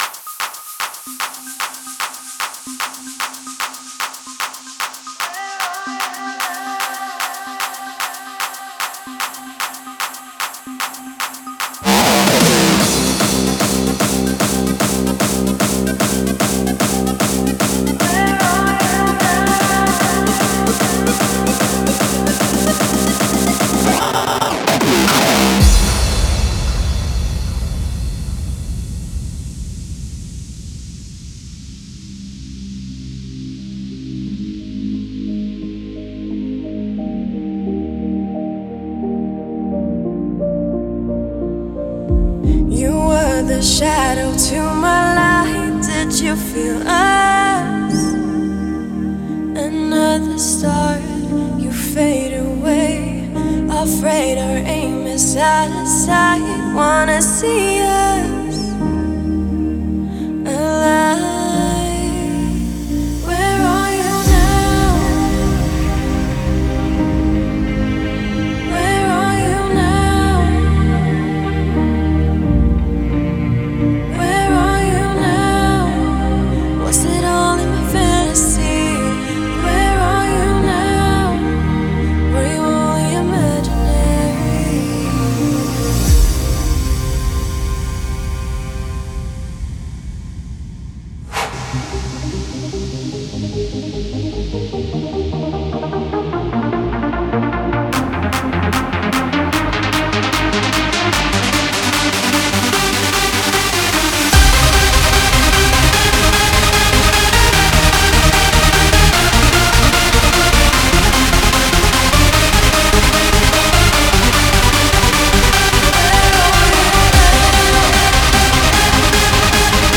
Index of: /data/localtracks/Hardstyle/